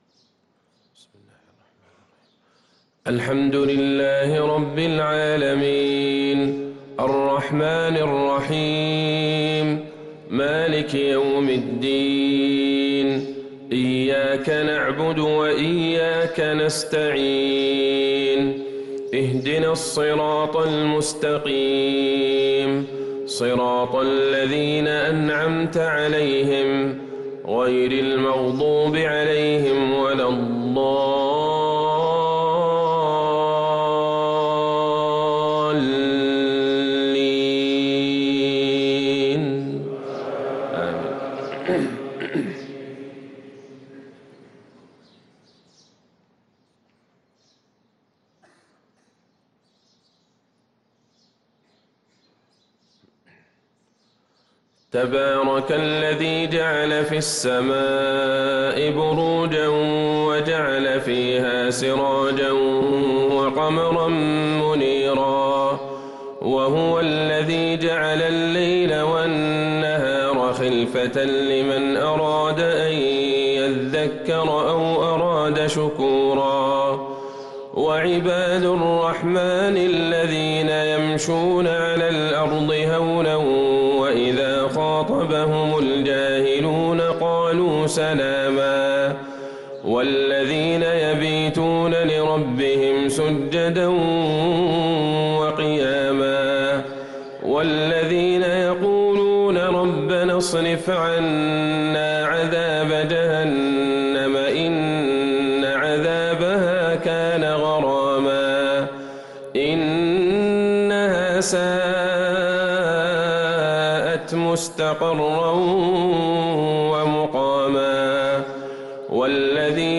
صلاة الفجر للقارئ عبدالله البعيجان 25 محرم 1444 هـ